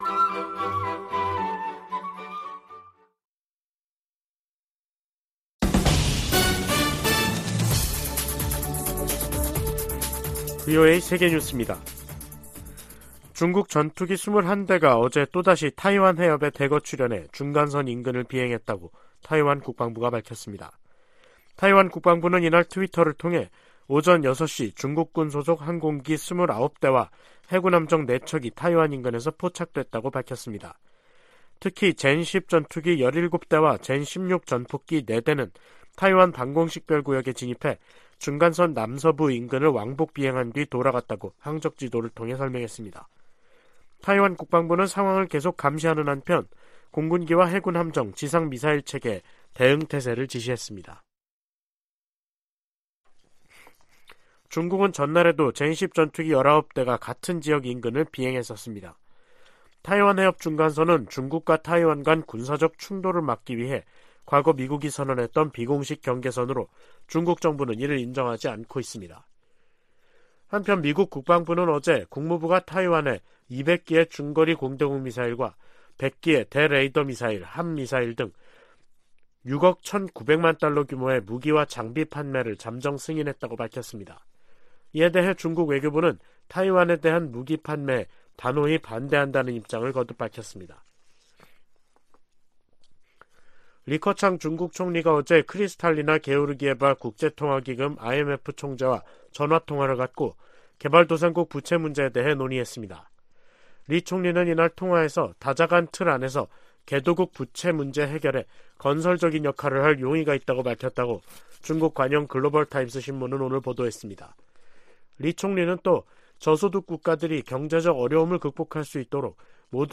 VOA 한국어 간판 뉴스 프로그램 '뉴스 투데이', 2023년 3월 2일 2부 방송입니다. 미 국무부는 윤석열 한국 대통령의 3∙1절 기념사가 한일관계의 미래지향적 비전을 제시했다며 환영의 뜻을 밝혔습니다. 최근 실시한 미한일 탄도미사일 방어훈련이 3국 협력을 증진했다고 일본 방위성이 밝혔습니다. 미 하원에 한국전쟁 종전 선언과 평화협정 체결, 미북 연락사무소 설치 등을 촉구하는 법안이 재발의됐습니다.